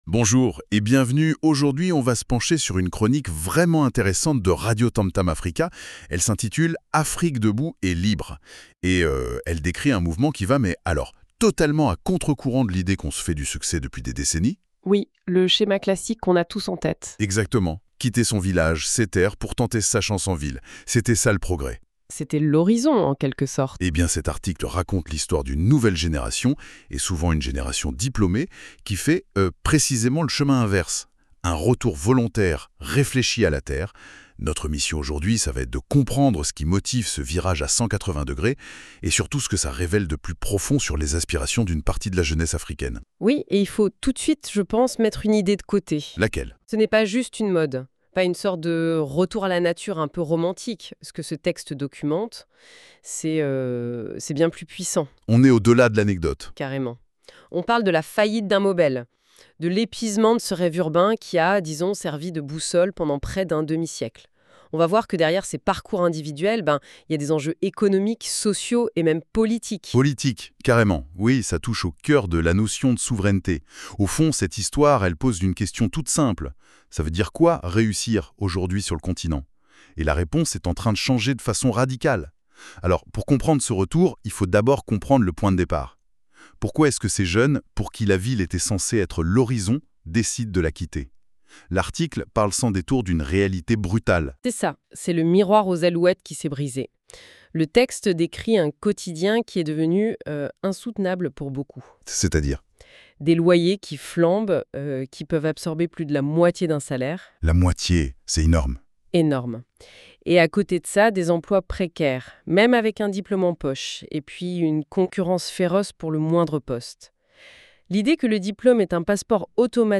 Nouvelle génération, souveraineté alimentaire et espoir économique. Une chronique engagée.